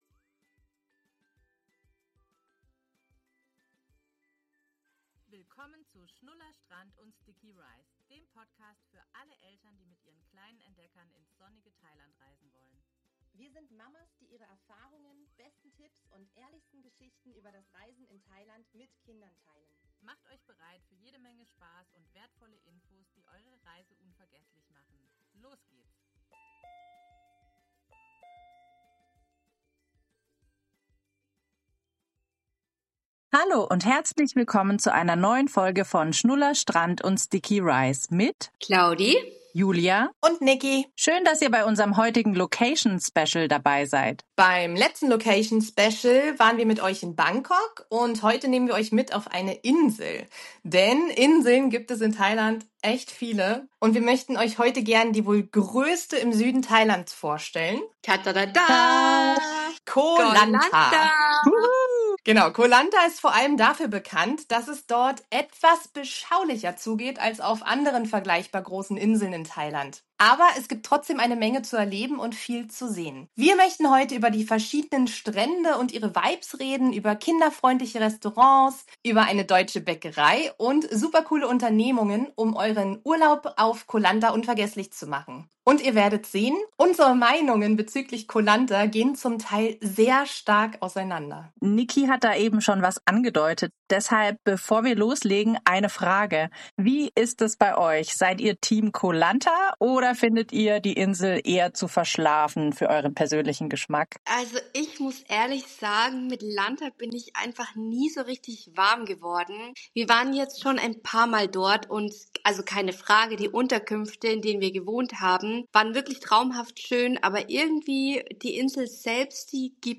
zwei Mamas mit Fernweh, Sonnencreme im Gepäck und ganz viel Herz für Thailand.